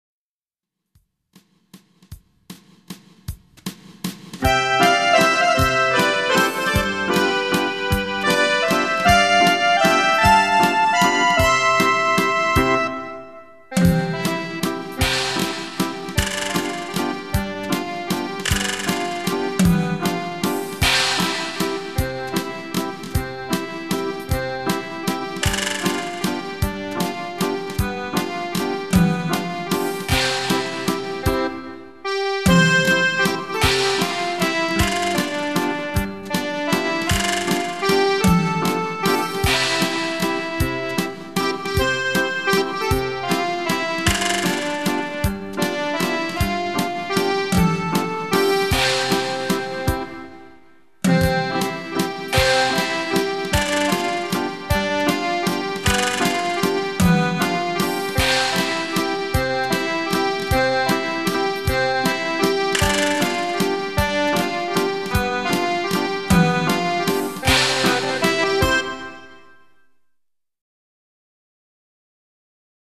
Audicions de flauta